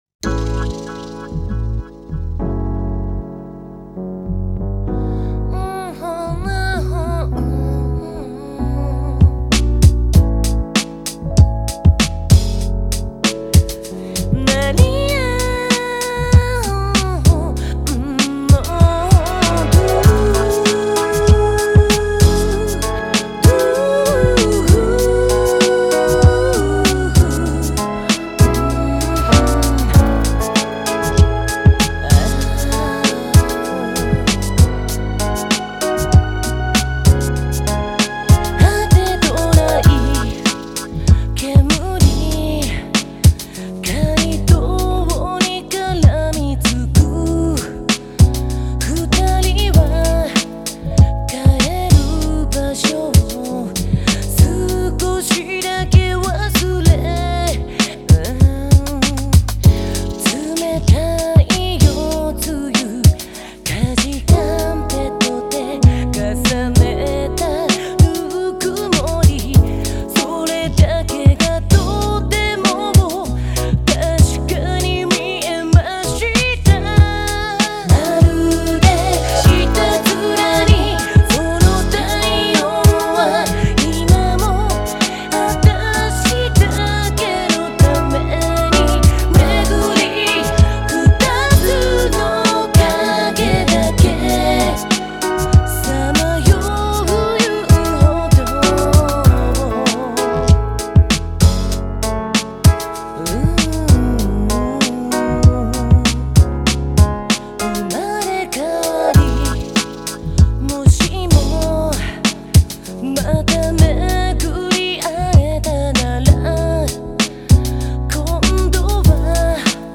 ジャンル(スタイル) JAPANESE POP